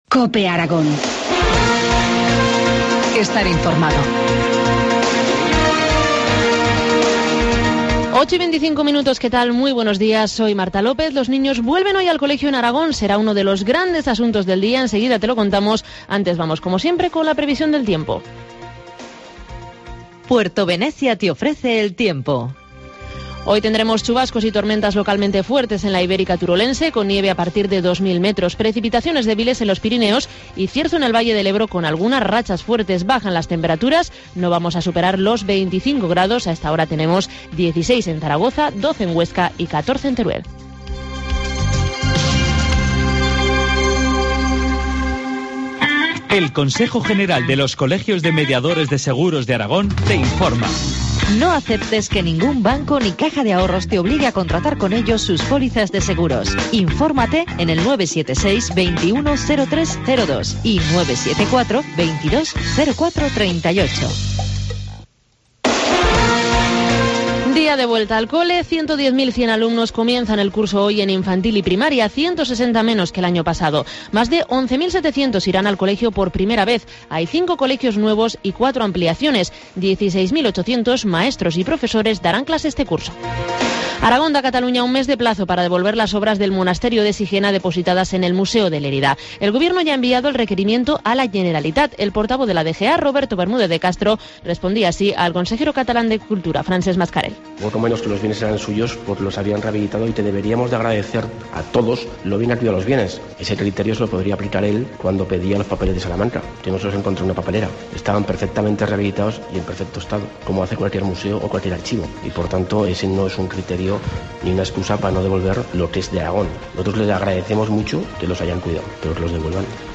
Informativo Matinal, miércoles 11 de septiembre, 8.25 horas